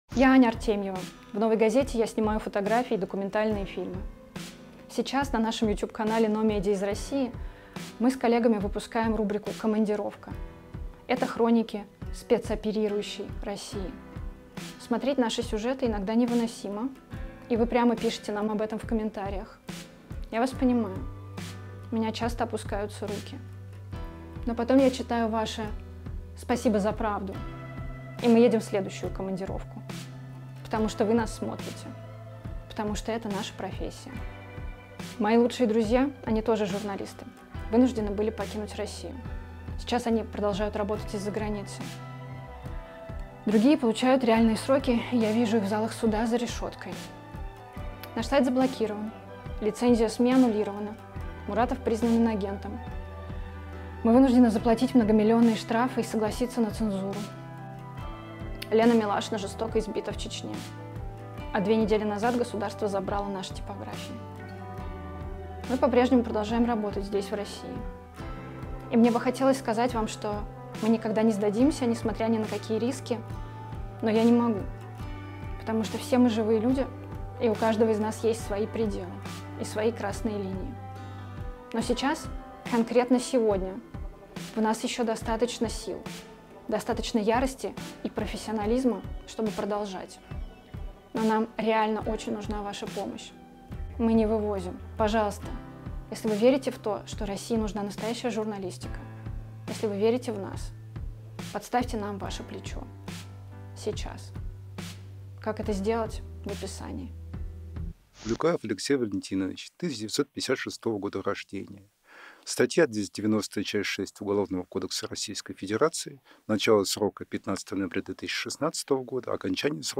Мы записываем его в студии в редакции «Новой газеты».